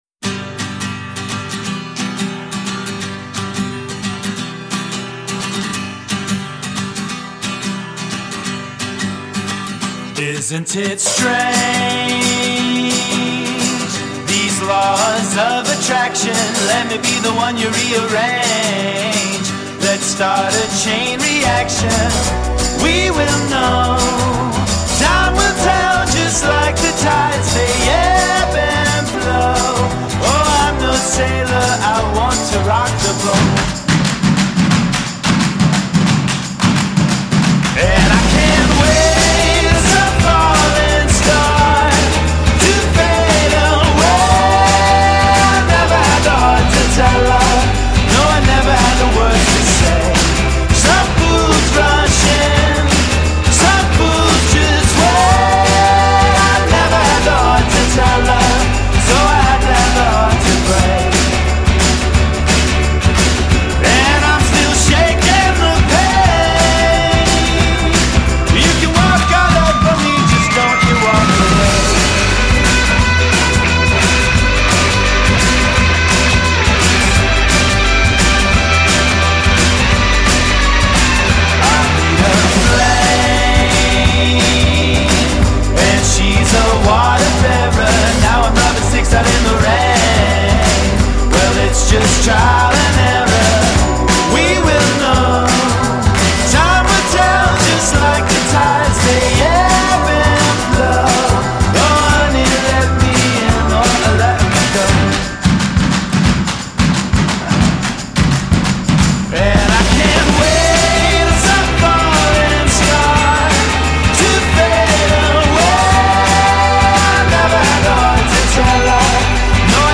sommerlichen song
leichtfüßigen melodien